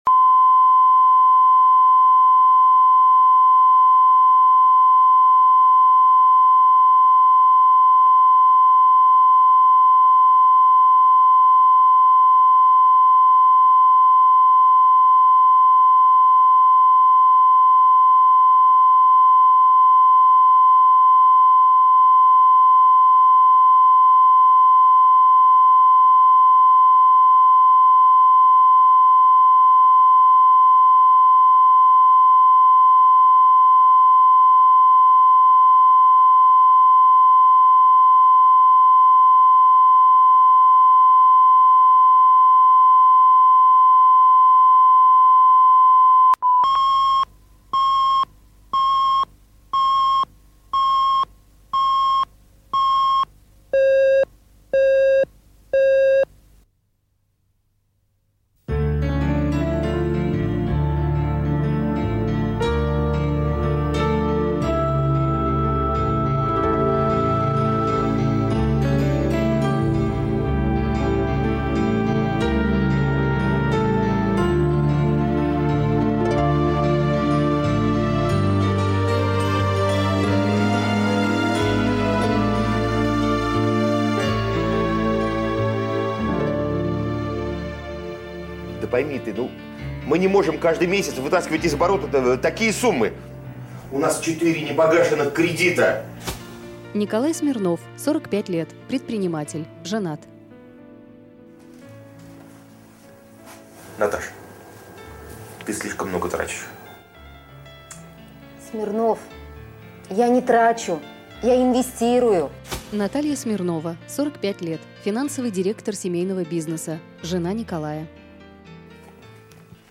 Аудиокнига Родственники